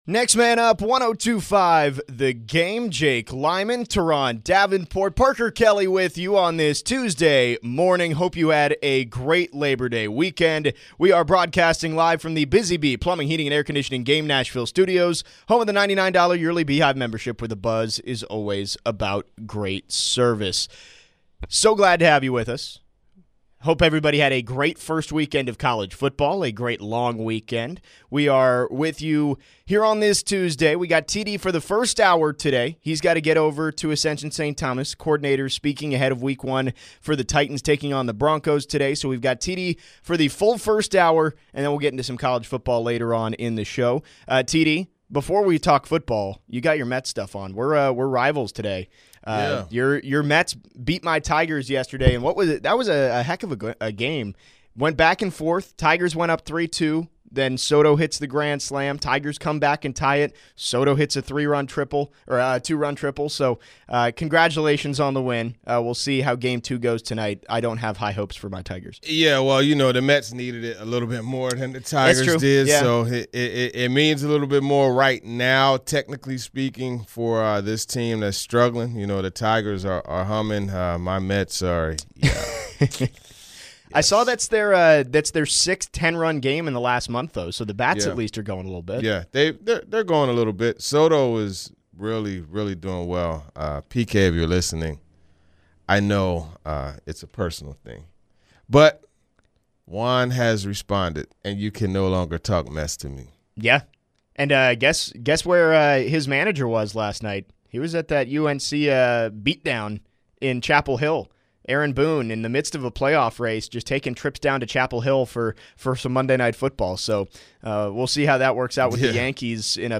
in studio to discuss fair expectations for Cam Ward in Week 1.